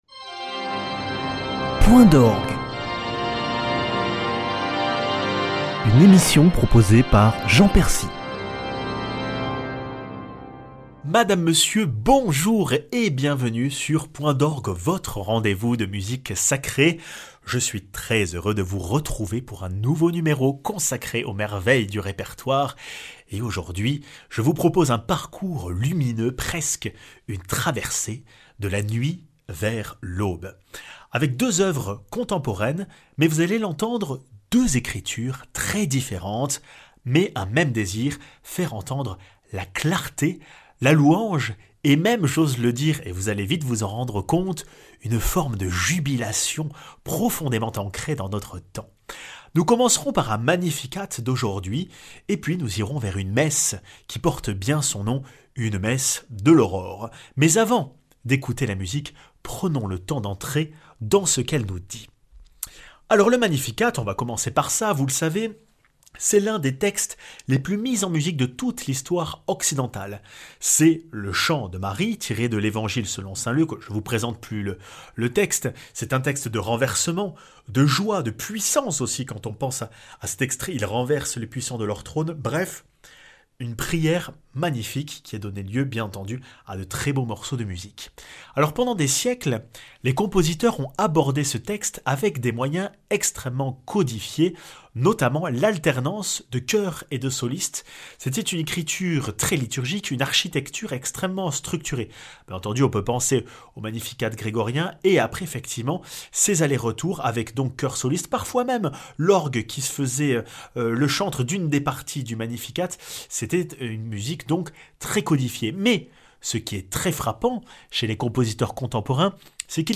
Parfois les instruments se mêlent au chant pour faire ressentir une joie immense portée dans les plus belles pages de la musique...